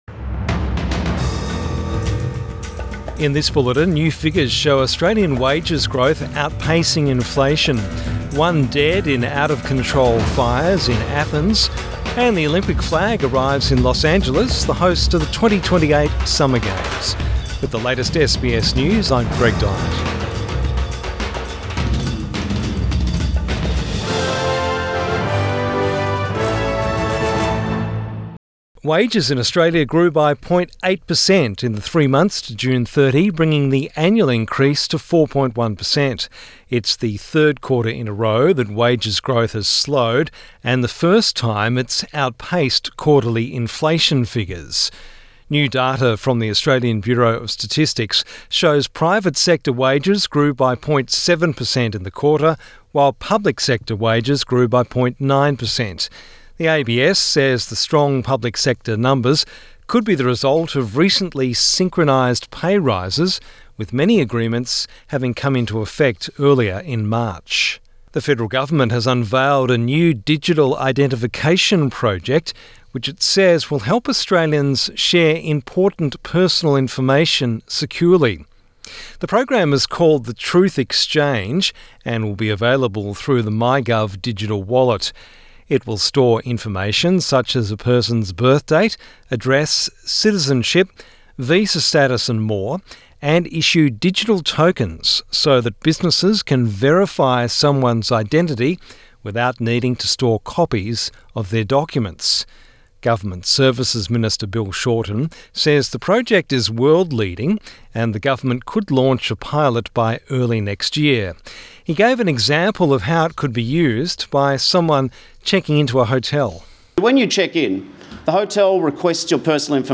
Evening News Bulletin 13 August 2024